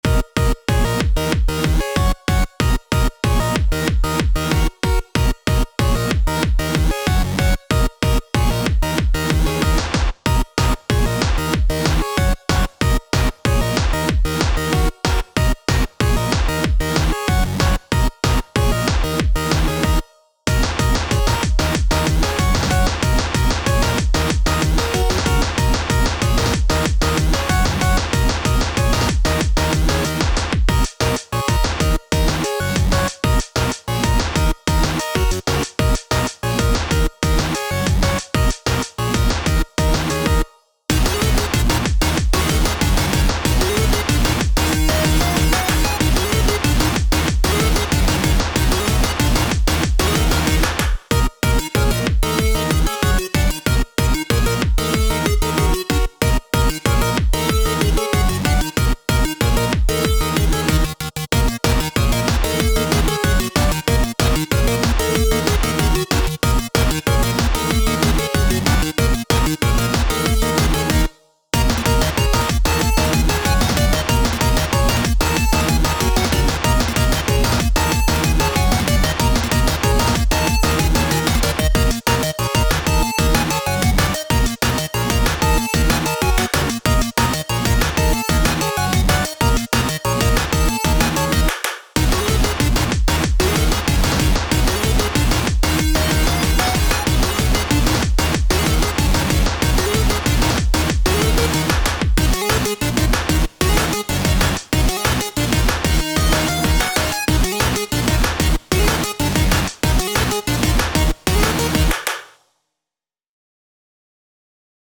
タグ: EDM ゲーム コミカル 電子音楽 音少なめ/シンプル コメント: ゲームサウンド風のシンセがメインの楽曲。